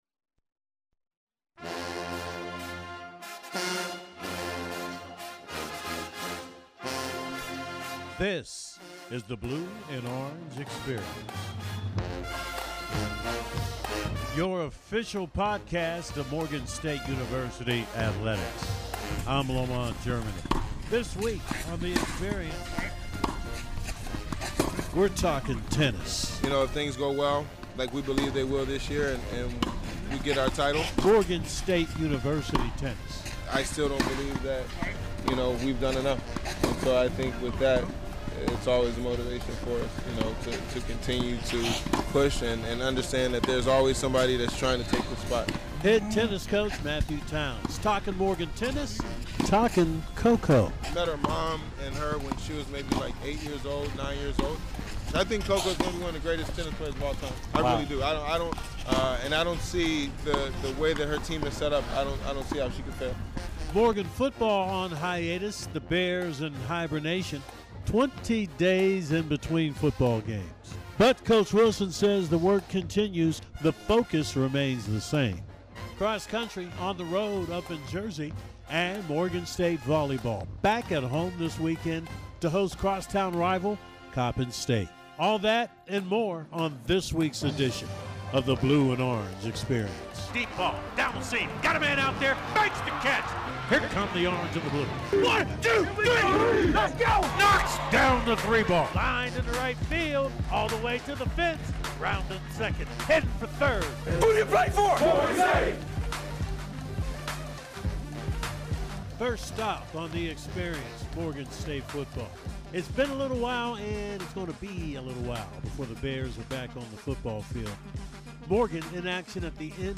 It explores the teams. coaches, student-athletes, alumni, stories and traditions of Morgan State University athletics. This edition of the BLUE & ORANGE Experience looks back on the week in Morgan State athletics and features an interview